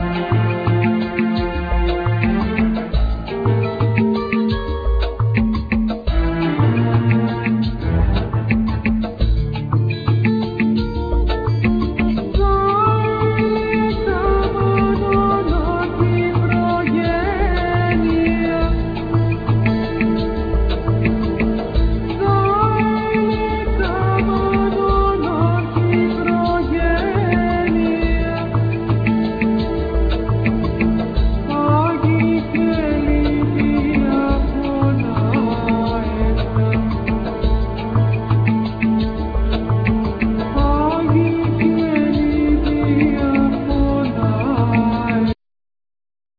Acoustic & Electric guitar,Bass
Piano,Synthsizer
Voice,Tenor Saxphone
Timpani
Small Clarinet
Tambur
Oboe